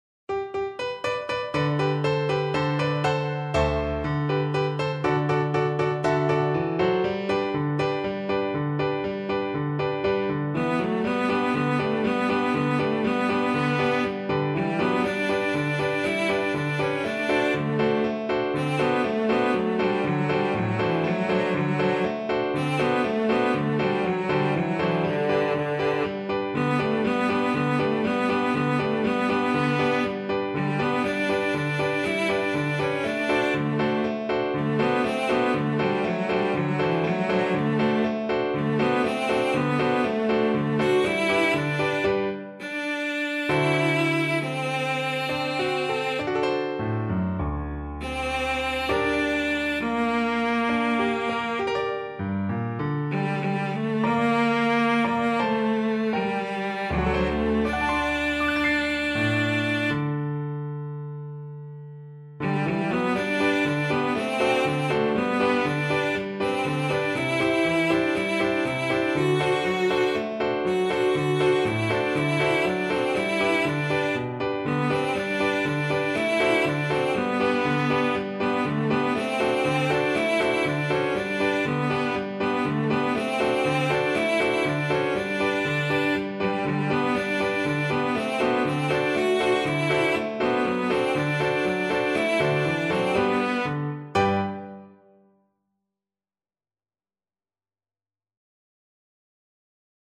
D4-F#5
2/4 (View more 2/4 Music)
Mariachi style =c.120
Traditional (View more Traditional Cello Music)
world (View more world Cello Music)